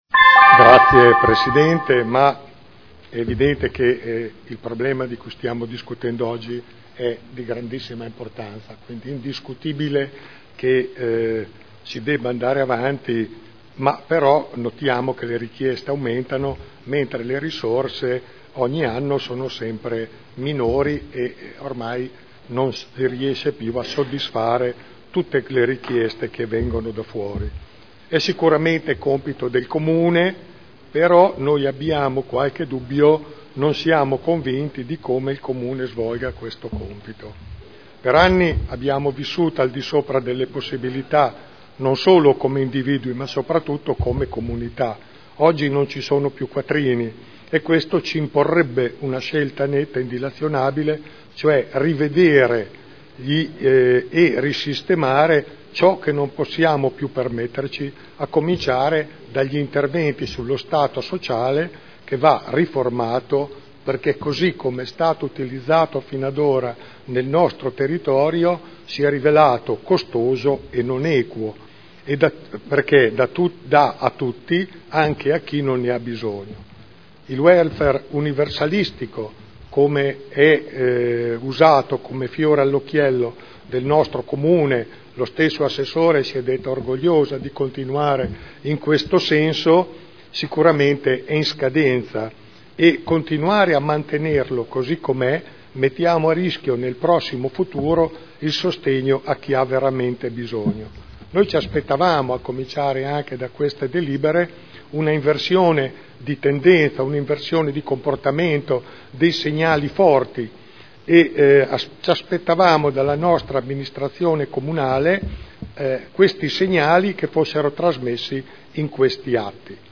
Dibattito. Linee di indirizzo per l’affidamento dei servizi di appoggio educativo assistenziale per gli alunni diversamente abili delle scuole di ogni ordine e grado site nel Comune di Modena (Commissione consiliare del 15 marzo e 28 marzo 2012)